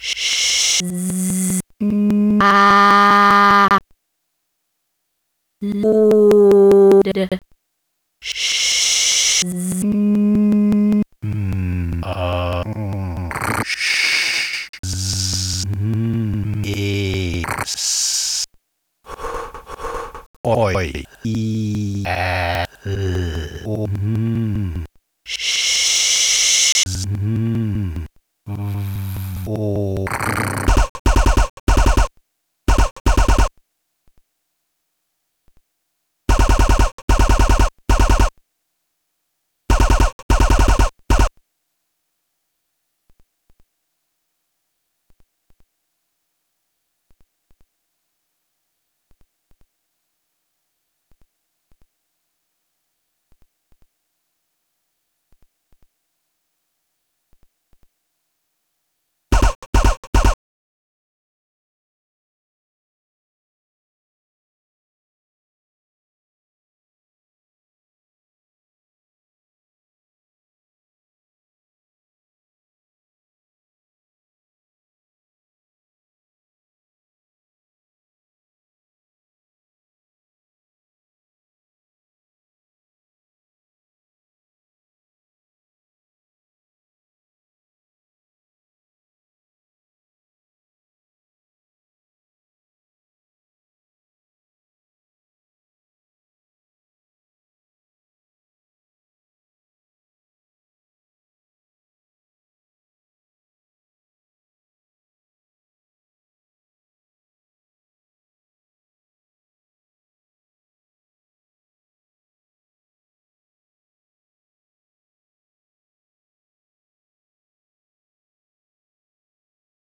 phonetic typewriter vorgeschützt (tribute to ernst jandl)
Ein kleines Opus Magnum, inklusive trügerischer Ruhe und Nachgefecht. Das Vorgeschützte im Titel und der Geschützklang, der in seiner Künstlichkeit an Videospiele erinnert, lassen an eine stofflos konkrete poesie denken.